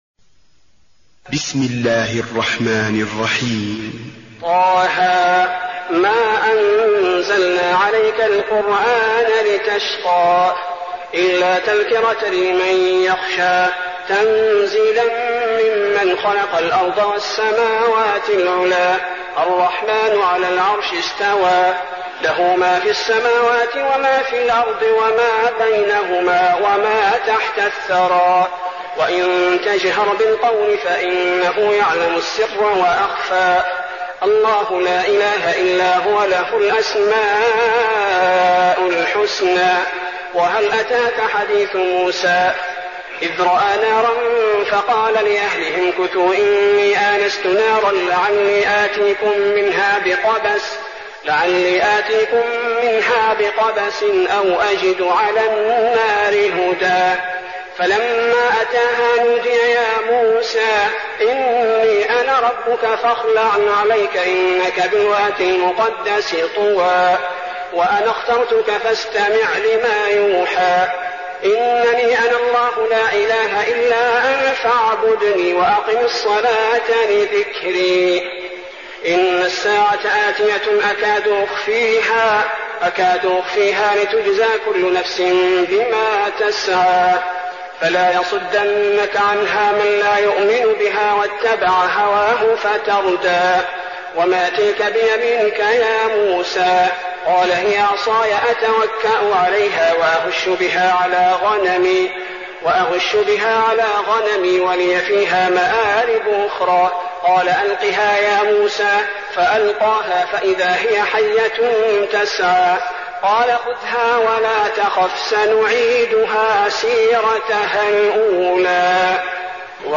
المكان: المسجد النبوي طه